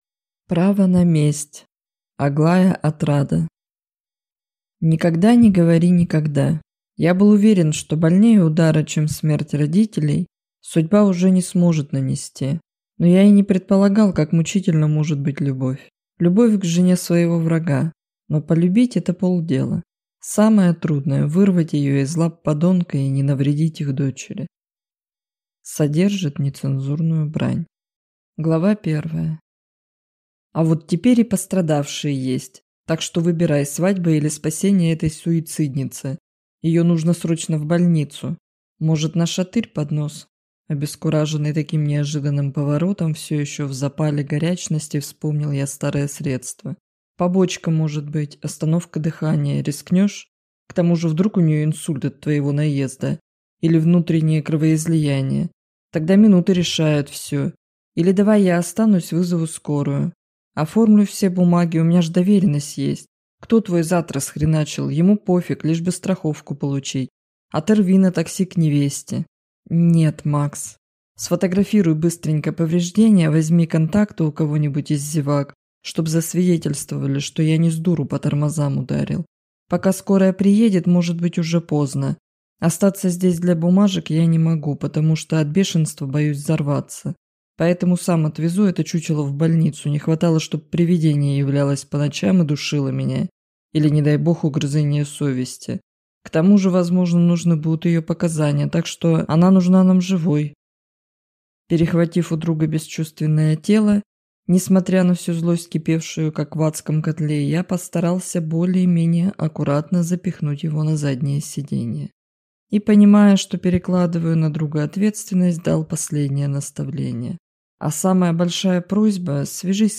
Аудиокнига Право на месть | Библиотека аудиокниг
Прослушать и бесплатно скачать фрагмент аудиокниги